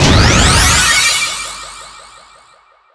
spawn_alternate2.wav